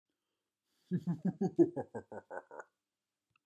Evil Laugh 1
Category 🗣 Voices
Deep Evil Laugh Mean Scary Spooky Villain Voices sound effect free sound royalty free Voices